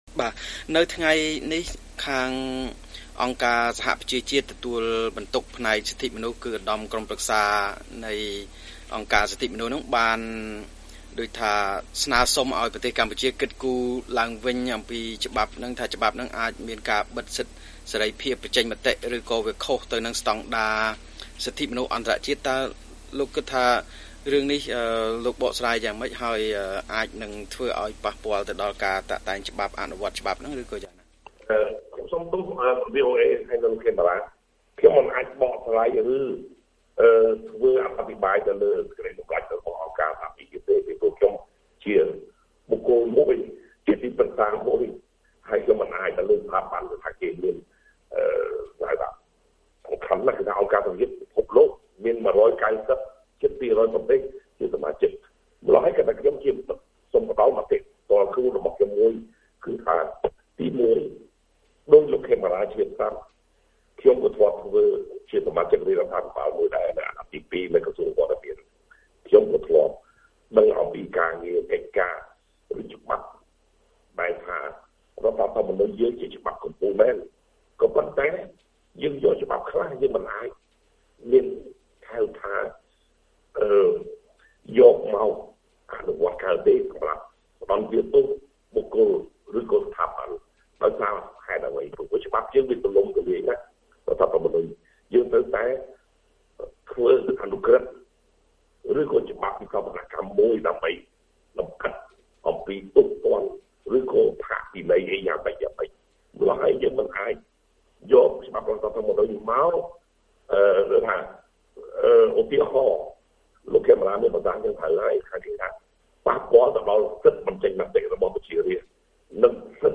បទសម្ភាសន៍ VOA៖ ទីប្រឹក្សាព្រះបរមរាជវាំងថាកម្ពុជាត្រូវការច្បាប់មិនឲ្យប្រមាថមហាក្សត្រ